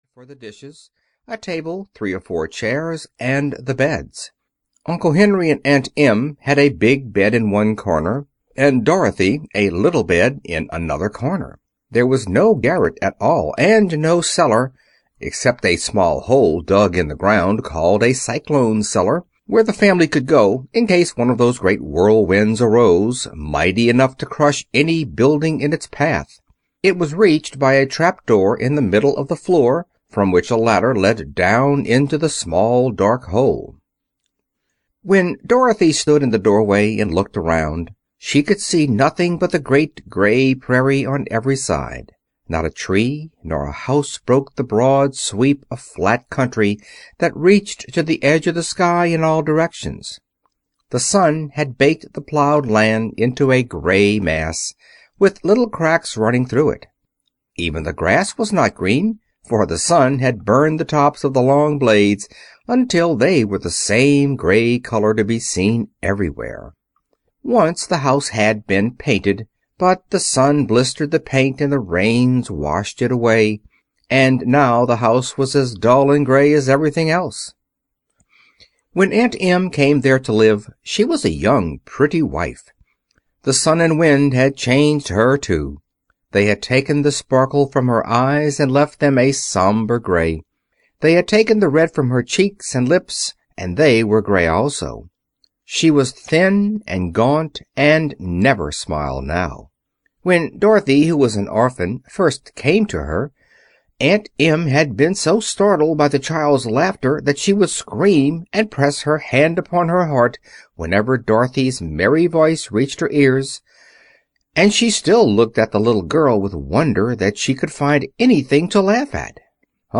The Wonderful Wizard of Oz (EN) audiokniha
Audiobook The Wonderful Wizard of Oz written by Lyman Frank Baum.
Ukázka z knihy